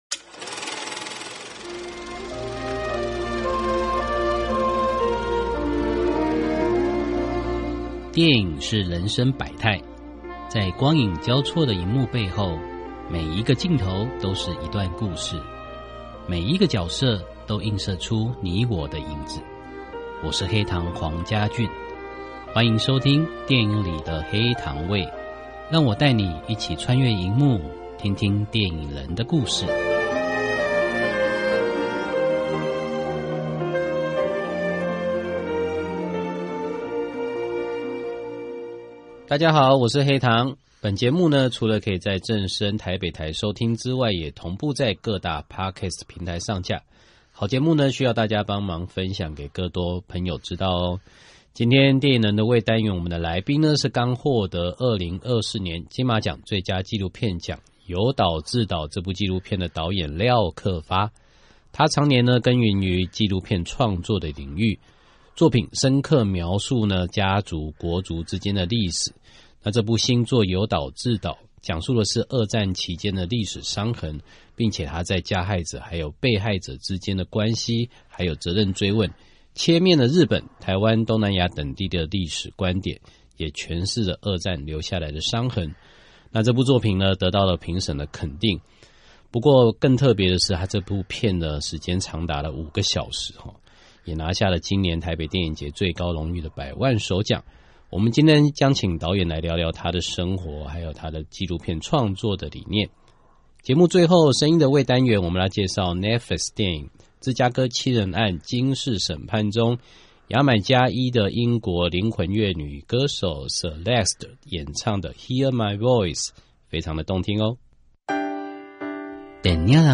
訪問大綱 1.